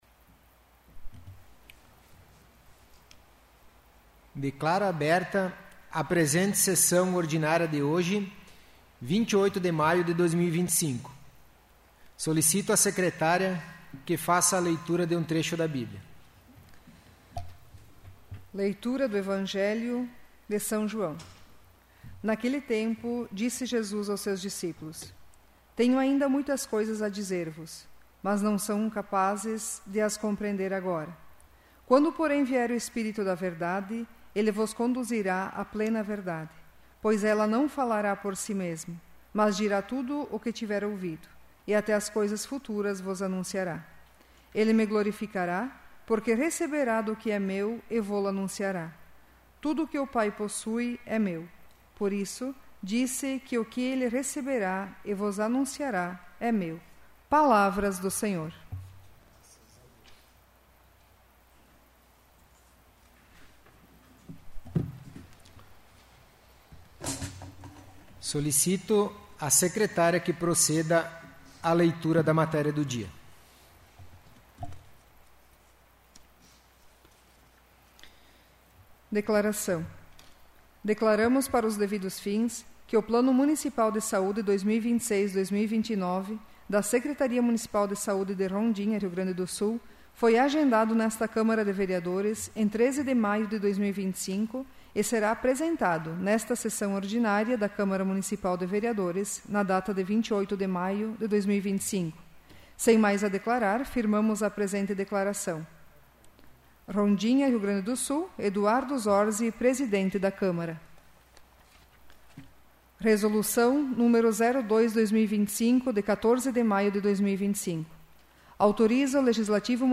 'áudio da sessão do dia 25/03/2026'